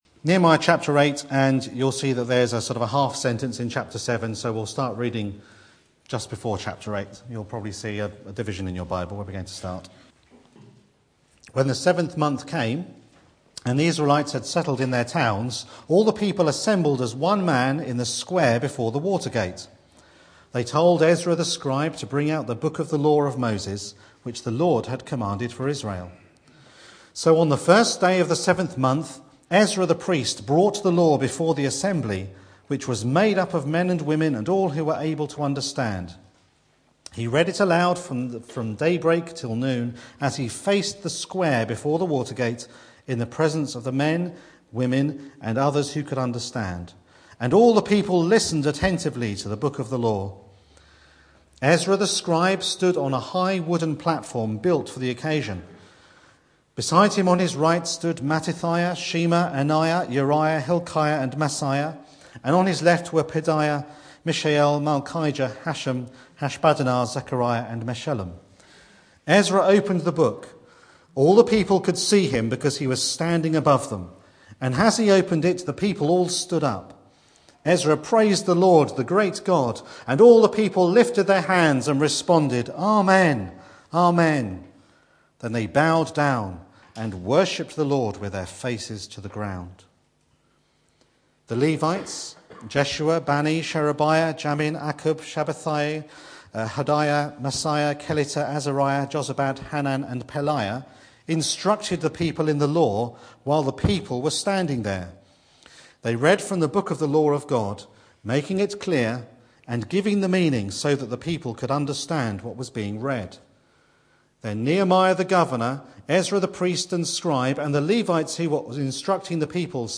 Back to Sermons The joy of the Lord is your strength